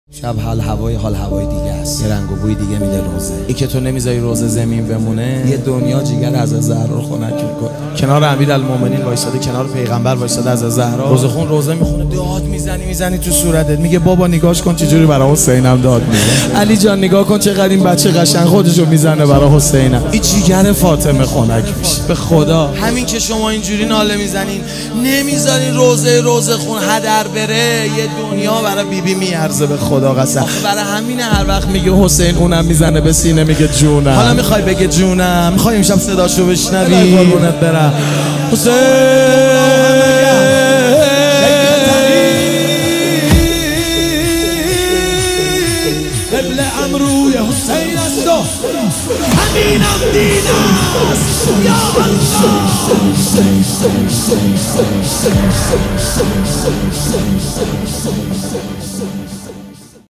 شهادت امام باقر (ع) | هیئت شباب الرقیه (س) 15 مرداد 98